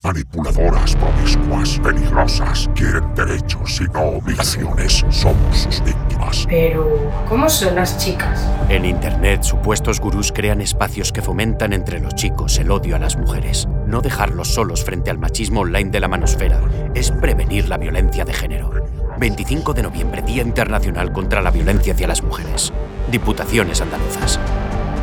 Cuña de radio